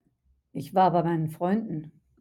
(Ich war bei MEI-nen FREUN-den)